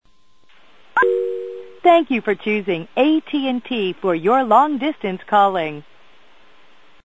The old style (version 2) Inter-LATA verification recording of AT&T long distance company.